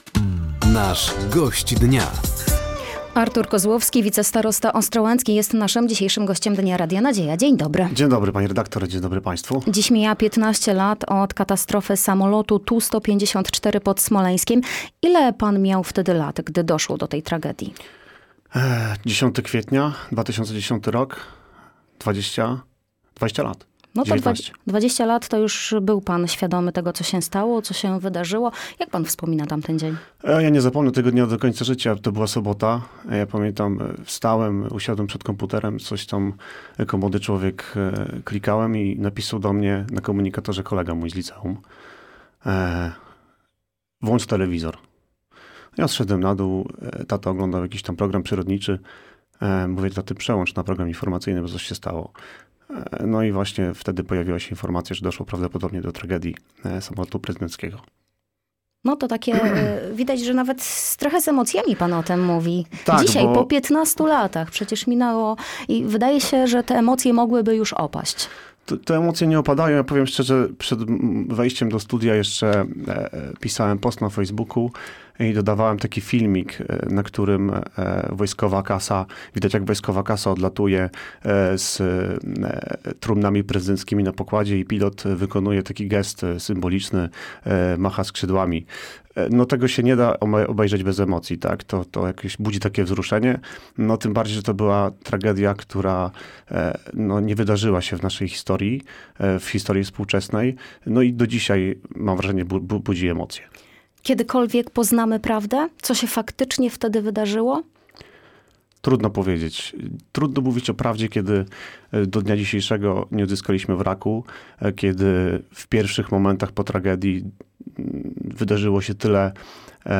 Znaczenie Centralnego Portu Komunikacyjnego dla północno-wschodniej części Polski, działania samorządowców na rzecz przywrócenia pierwotnego projektu szprychy nr 3 oraz podsumowanie I Forum Edukacji i Rynku Pracy organizowanego przez Powiat Ostrołęcki – to główne tematy rozmowy z gościem Dnia Radia Nadzieja.
O szczegółach opowiadał wicestarosta ostrołęcki, Artur Kozłowski.